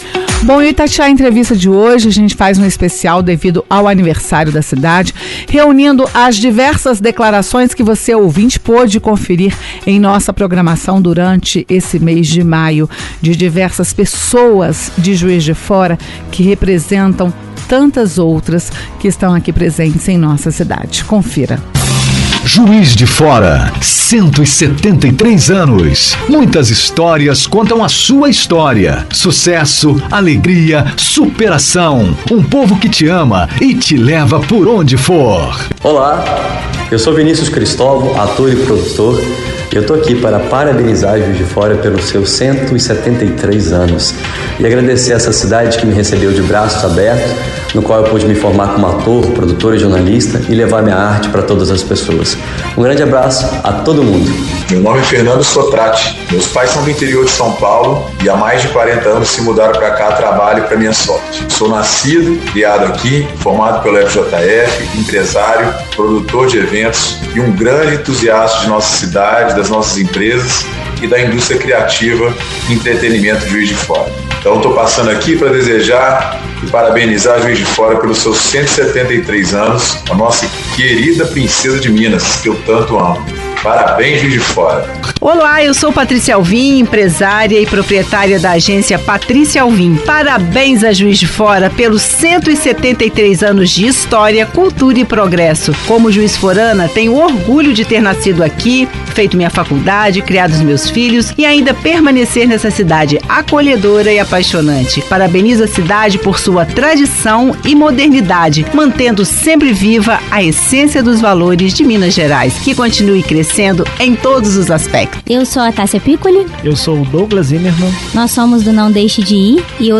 Ouça as declarações de pessoas que representam tantas outras em Juiz de Fora.